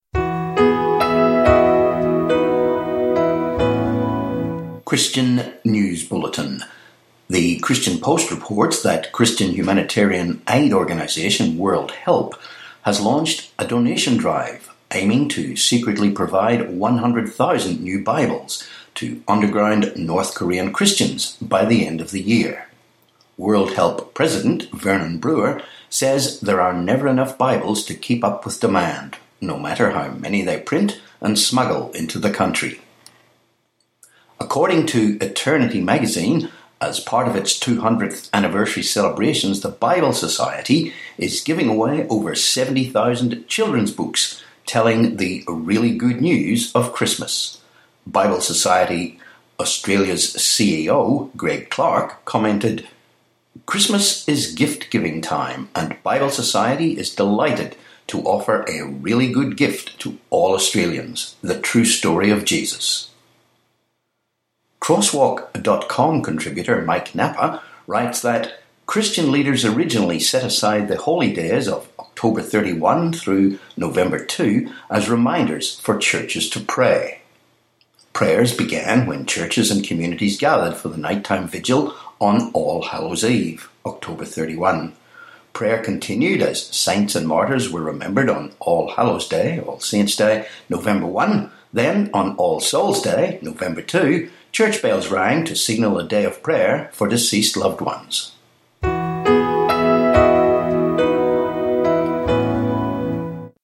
29Oct17 Christian News Bulletin